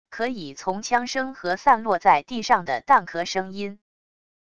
可以从枪声和散落在地上的弹壳声音wav音频